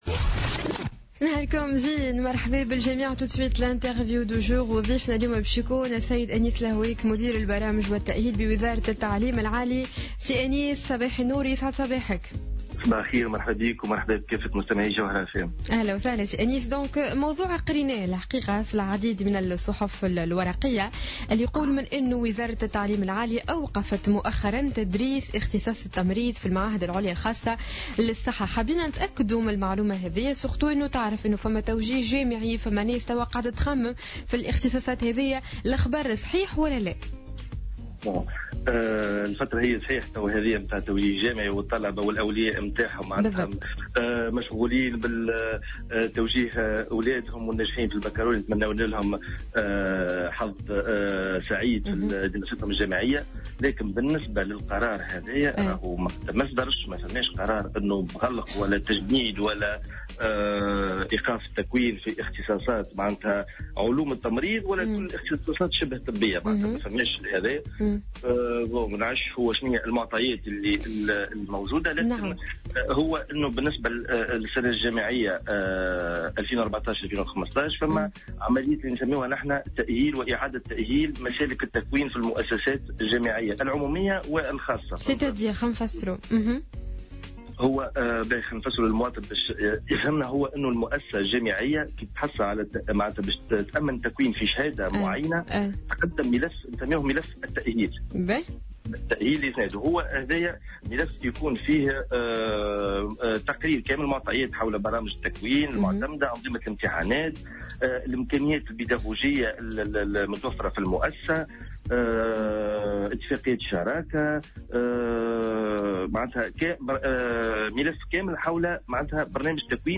تصريح لجوهرة "اف ام"